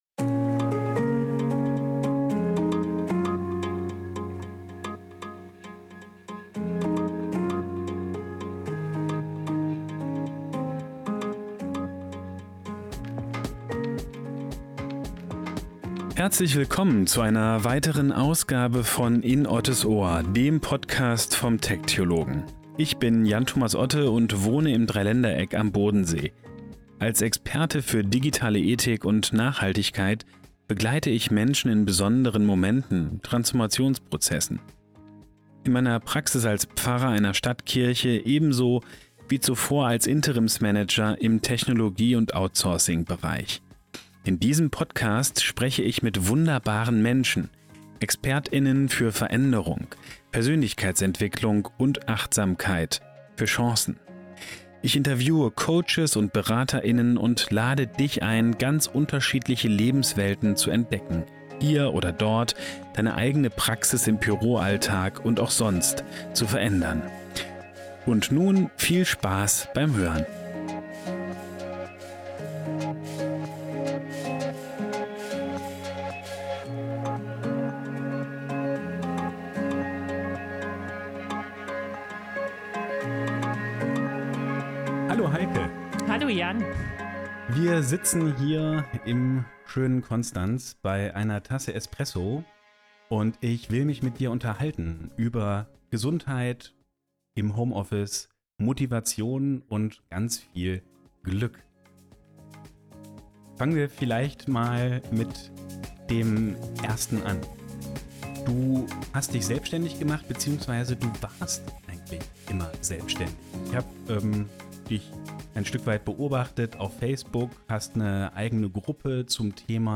Ich habe mich mit der Gesundheitsberaterin darüber unterhalten.